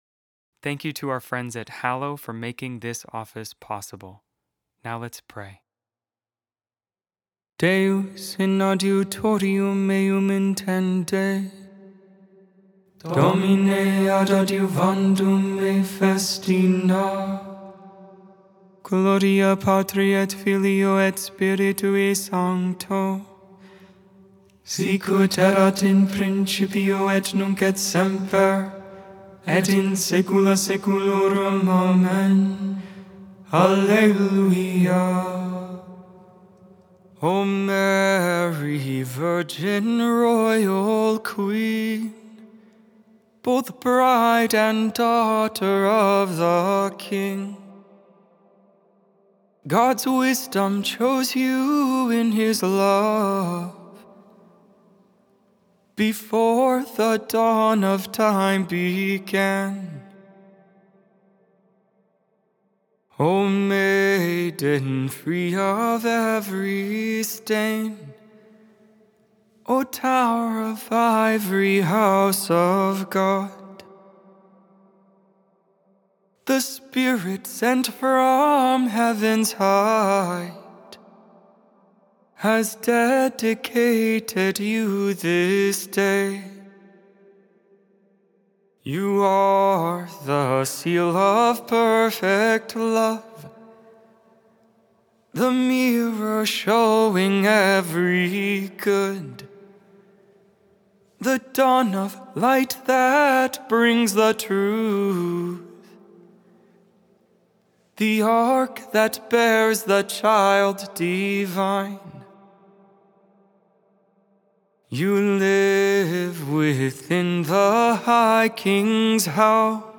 Vespers, Evening Prayer for the 33rd Friday of Ordinary Time, November 21, 2025.Memorial of the Presentation of the Blessed Virgin MaryMade without AI. 100% human vocals, 100% real prayer.